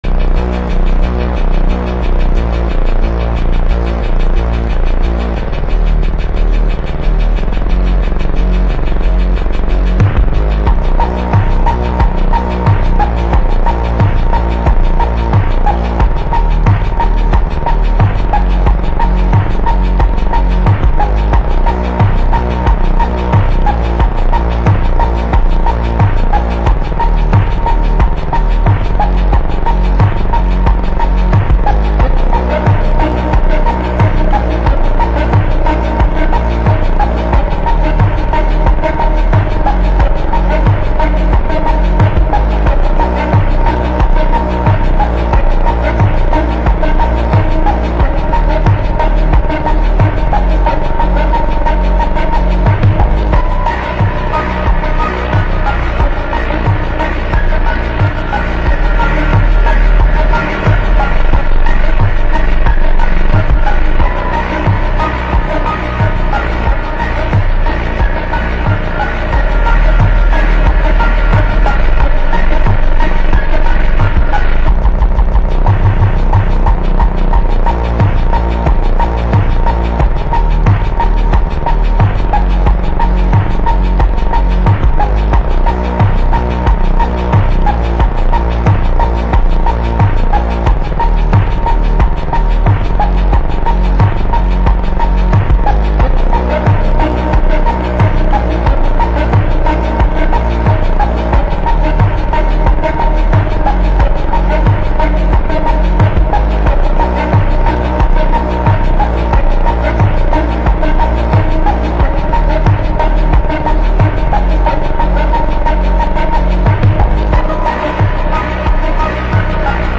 در ورژن Slowed و کند شده با وایبی دارک مخصوص ادیت و ورزش
فانک